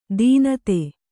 ♪ dīnate